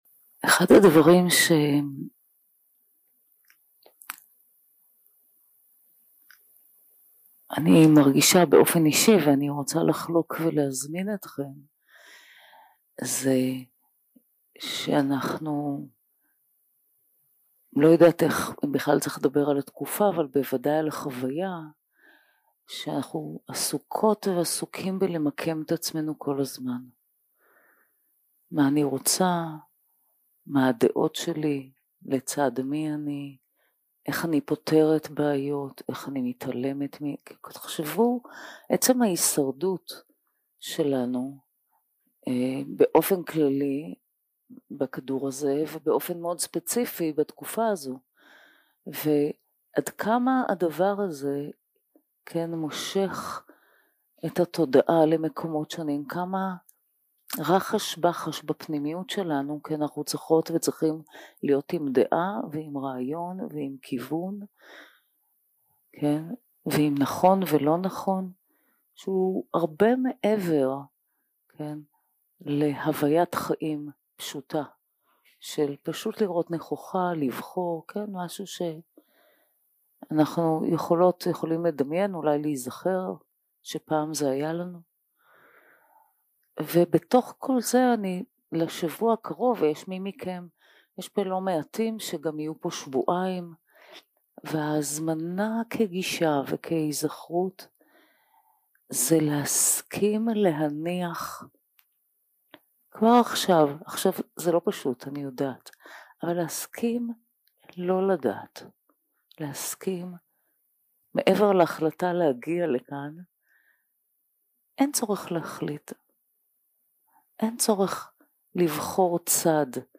יום 1 – הקלטה 1 – ערב – מדיטציה מונחית
Dharma type: Guided meditation